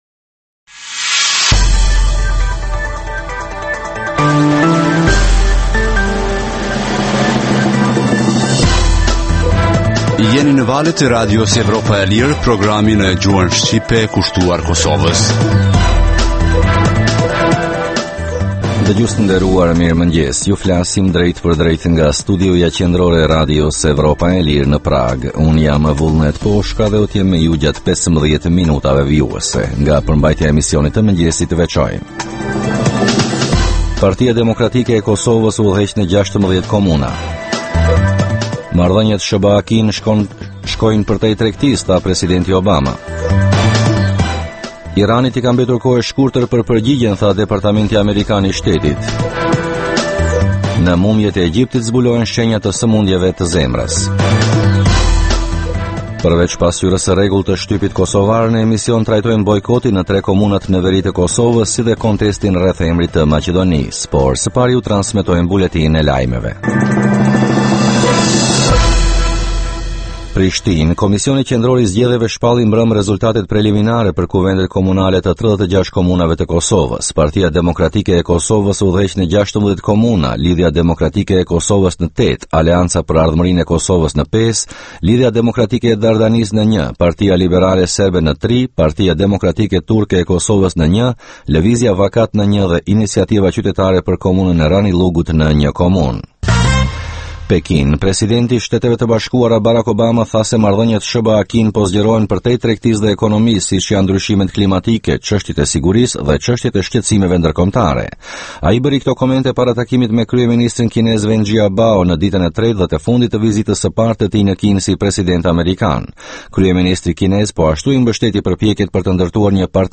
Emisioni i mëngjesit rëndom fillon me buletinin e lajmeve nga Kosova, rajoni dhe bota.